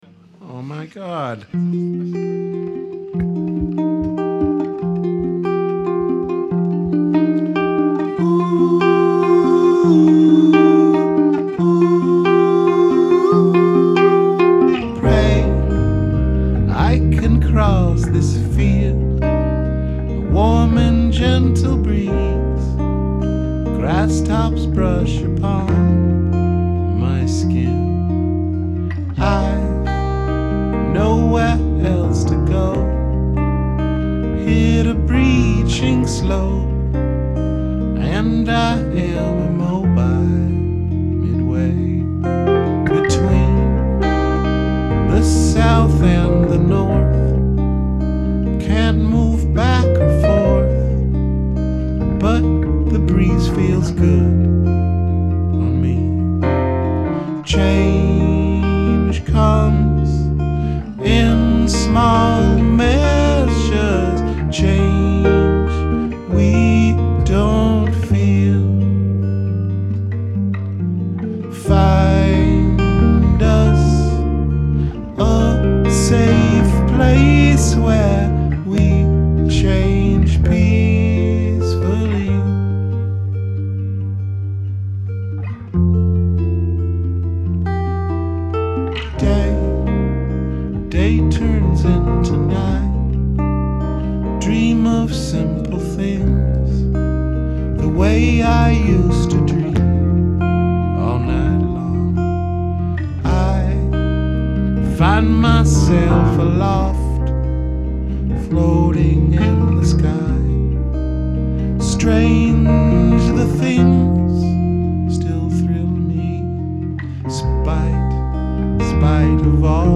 Rehearsals 25.2.2012